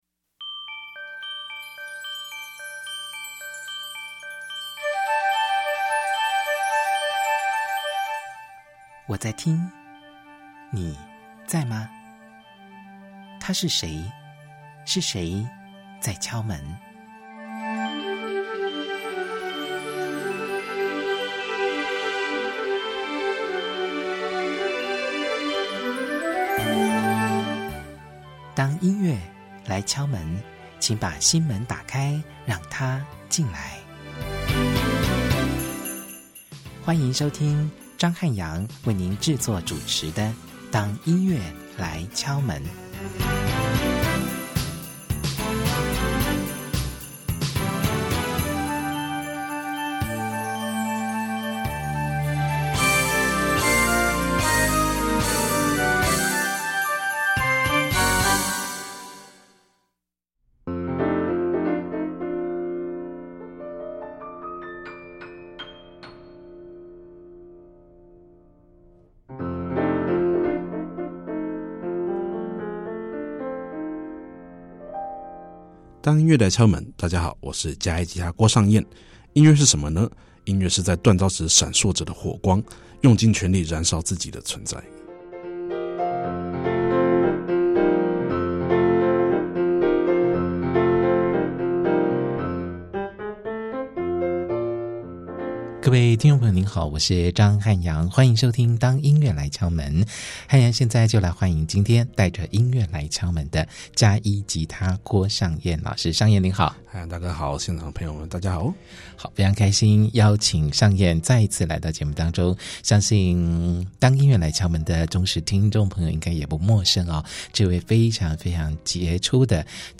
訪問